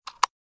CitadelStationBot 14427a2b8c Ballistic Weapon/Shotgun Reload Sounds 2017-05-24 05:52:37 -05:00 8.3 KiB Raw History
bulletinsert.ogg